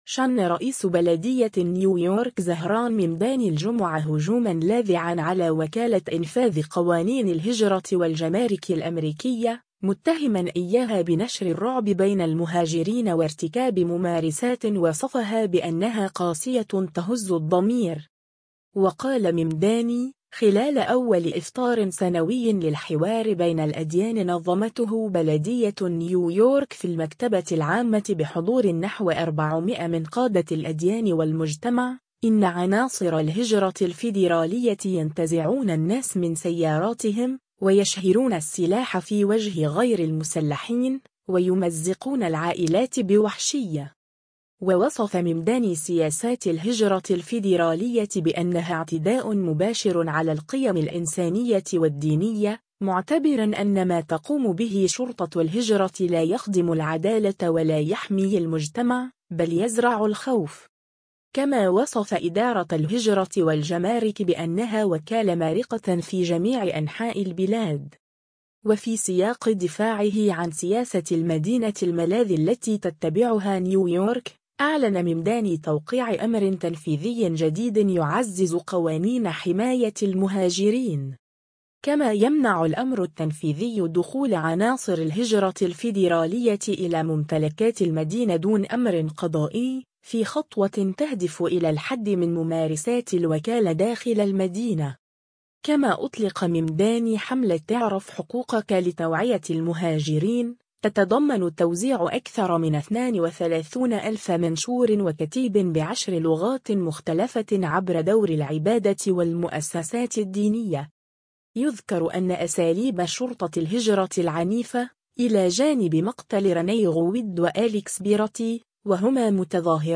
و قال ممداني، خلال أول إفطار سنوي للحوار بين الأديان نظمته بلدية نيويورك في المكتبة العامة بحضور نحو 400 من قادة الأديان والمجتمع، إن عناصر الهجرة الفدرالية “ينتزعون الناس من سياراتهم، ويشهرون السلاح في وجه غير المسلحين، ويمزقون العائلات بوحشية”.